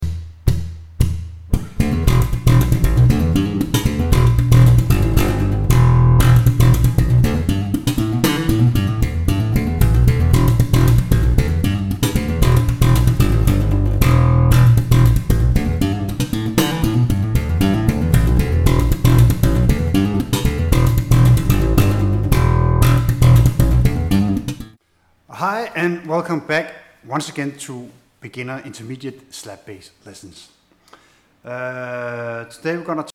05 Slap bass 101 for novice slappers
05-Slap-bass-101Sample.mp3